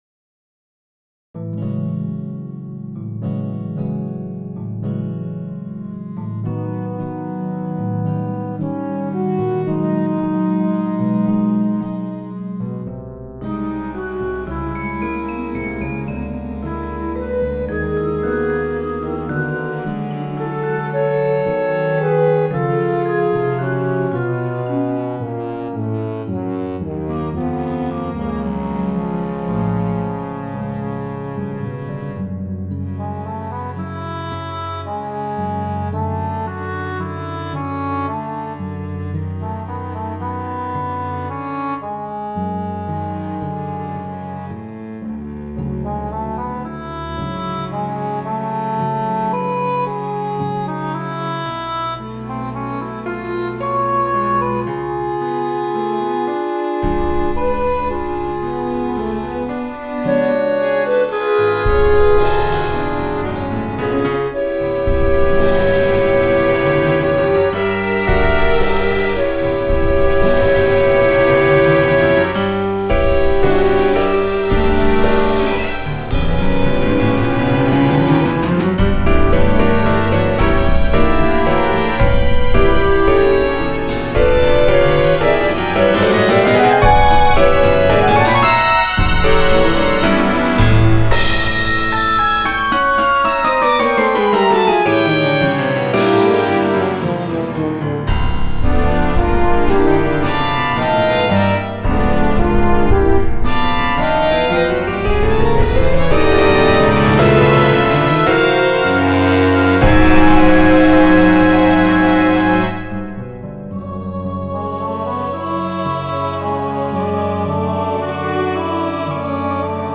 French horn, Violin, Cello,
Guitar (acoustic and electric), and